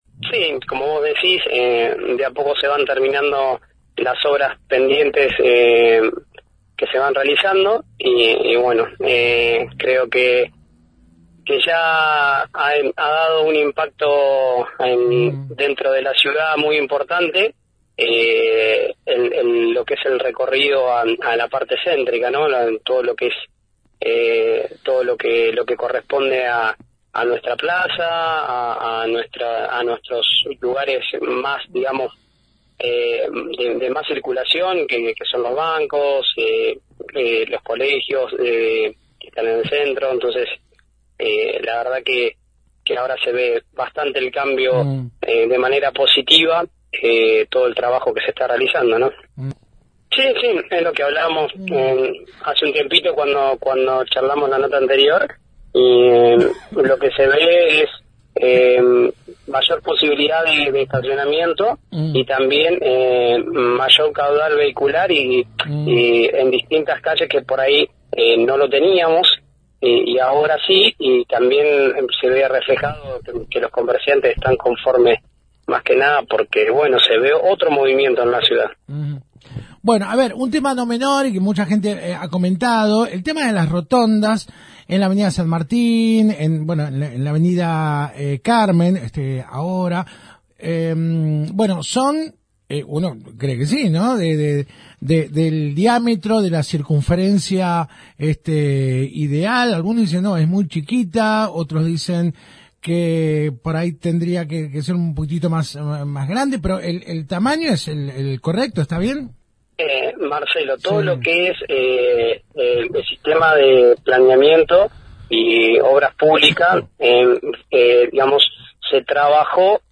El Coordinador General de Infraestructura Vial, Hugo Vázquez, se refirió este martes en FM Alpha al nuevo Plan de Ordenamiento Urbano que el lunes pasado sumó un nuevo capítulo con el doble sentido de circulación en Avda. Rivadavia entre Avda. San Martín y Avda. Carmen (frente al municipio) como así también los nuevos reductores de velocidad en Avda. Sarmiento entre Alcorta y Pasteur y la nueva señalética en el circuito del Parque Plaza Montero.